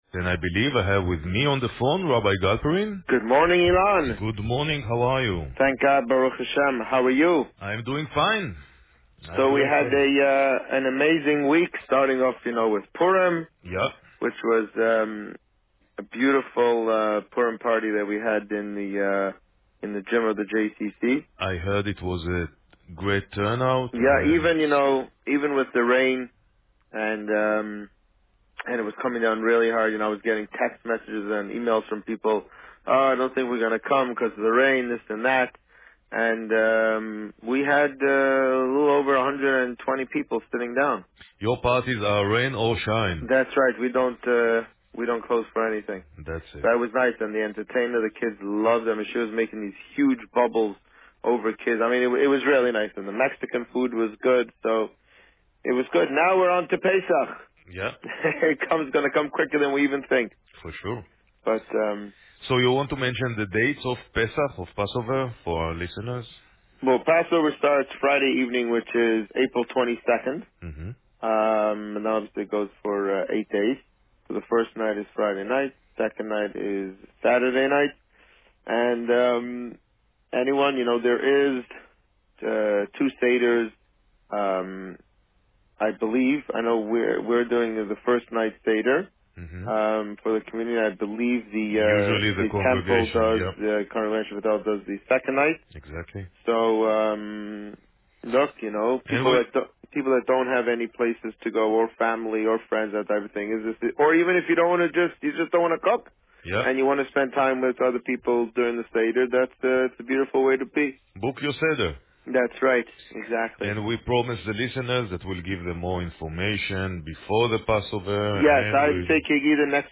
On March 31, 2016, the Rabbi spoke about the well-attended Purim party at the Jewish Community Centre, as well as Parsha Shemini and the upcoming visit from a New Jersey NCSY chapter. Listen to the interview here.